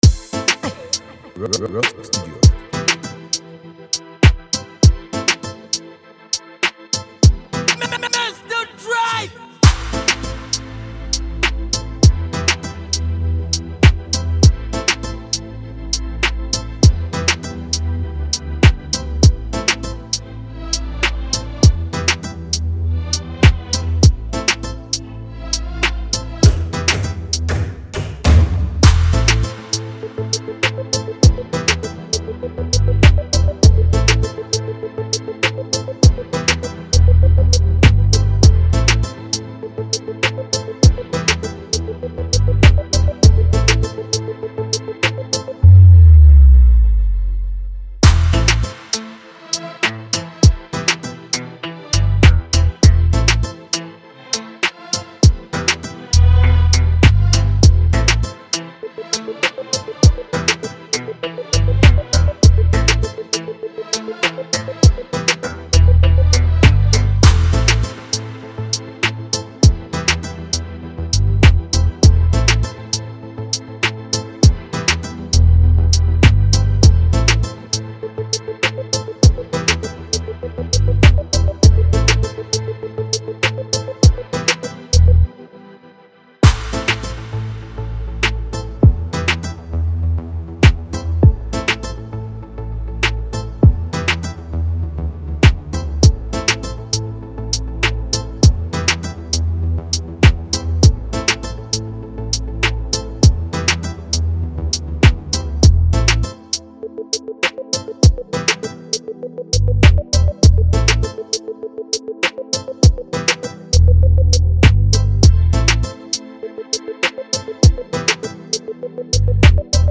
Listen to this free beat and do what you do best with it.